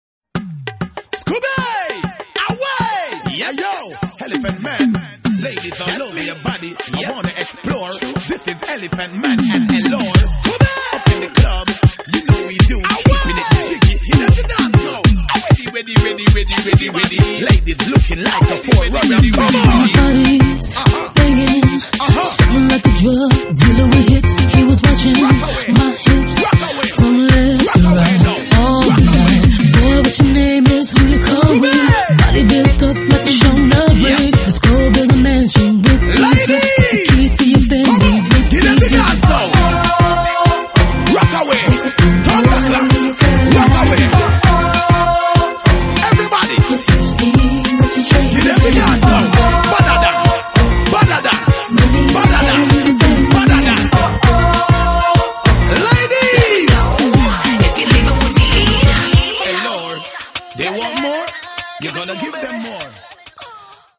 Sorties R'nB
dynamique, joyeux et dansant